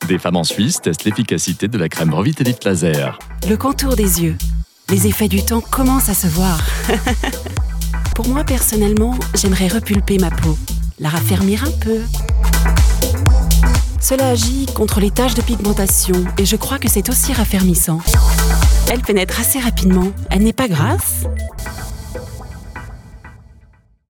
Female
Approachable, Assured, Authoritative, Bright, Bubbly, Character, Children, Confident, Conversational, Corporate, Deep, Energetic, Engaging, Friendly, Gravitas, Natural, Reassuring, Smooth, Soft, Versatile, Warm, Witty
Microphone: TLM Neumann 103